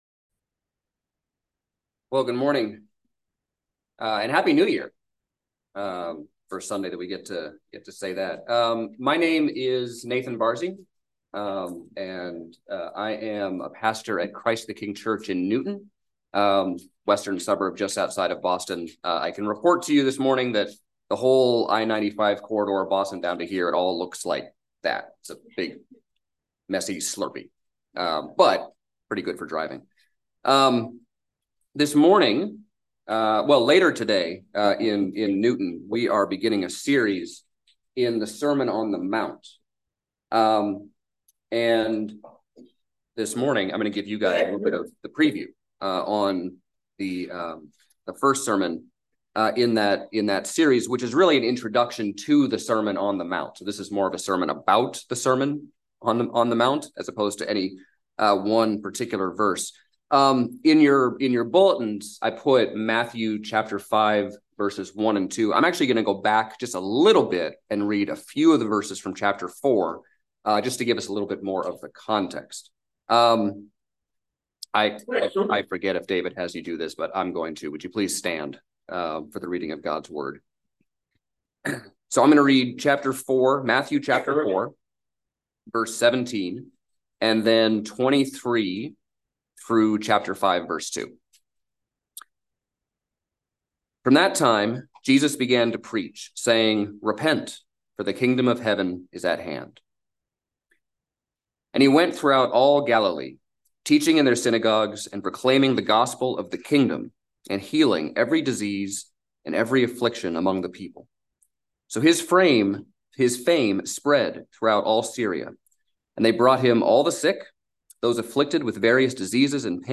by Trinity Presbyterian Church | Jan 10, 2024 | Sermon